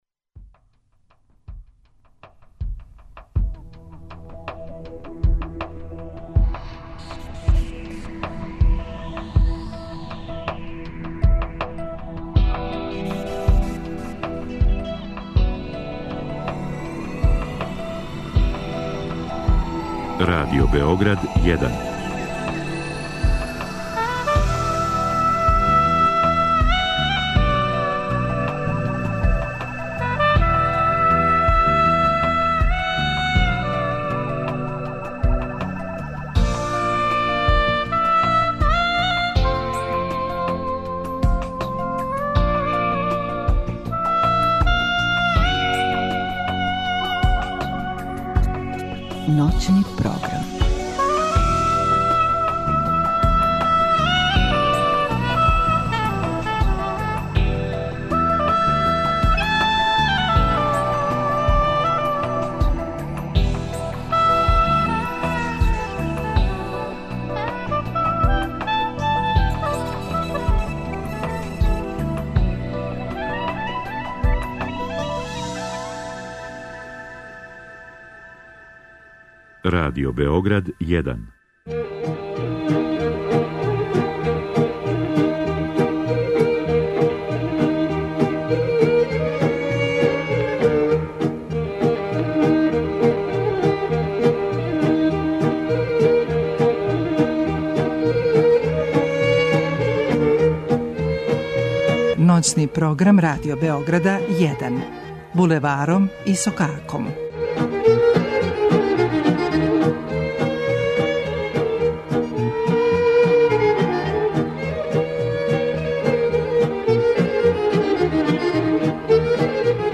Само на Радио Београду један можете чути традиционалну и стилизовану народну музику.